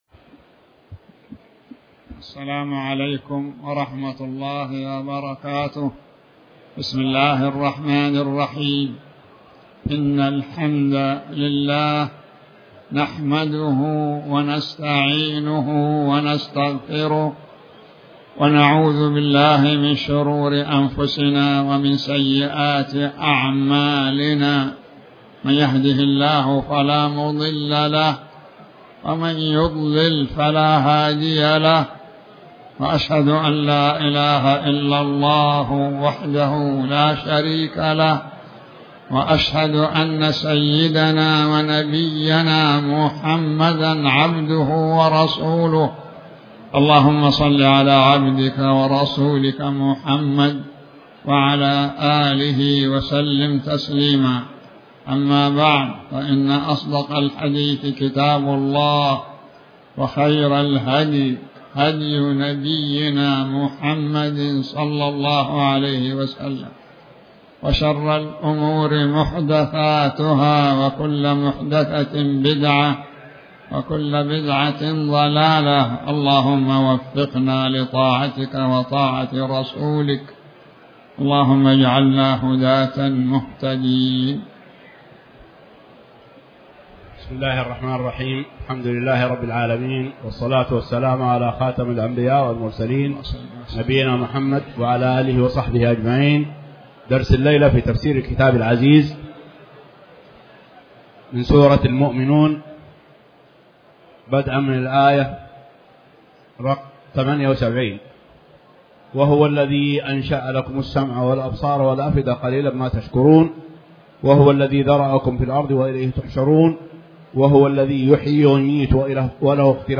تاريخ النشر ٢٨ محرم ١٤٤٠ هـ المكان: المسجد الحرام الشيخ